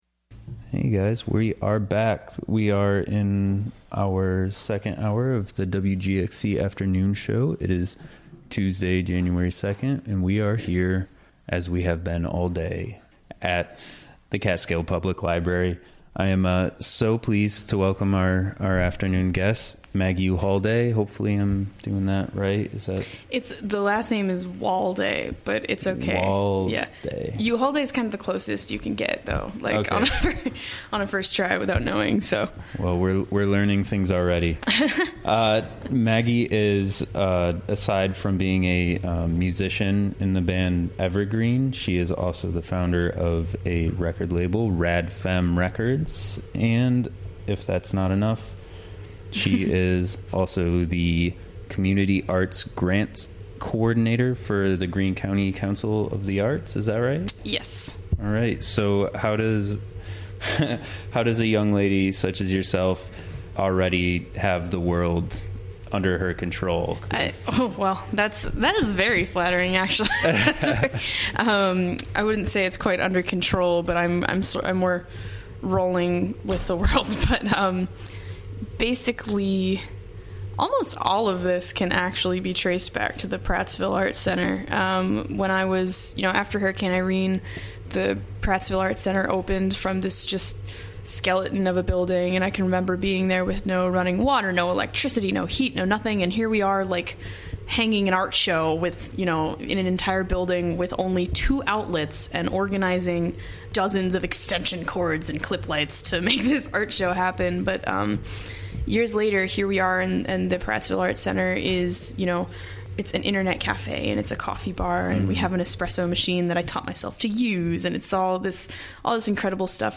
Recorded during the WGXC Afternoon Show of Tuesday, Jan. 2, 2018.